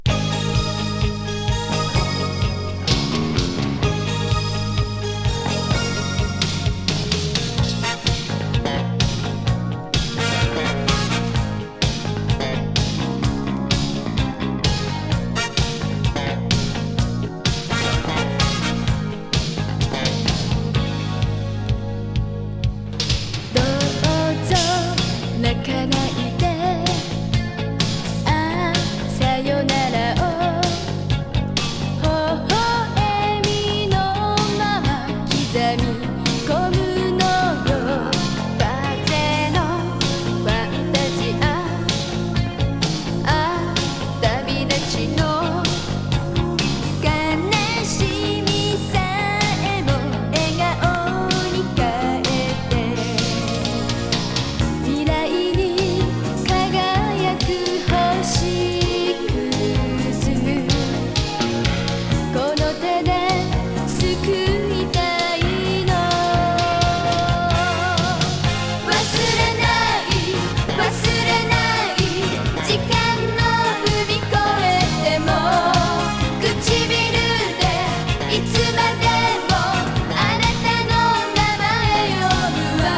Japanese 80's ロック / ポップス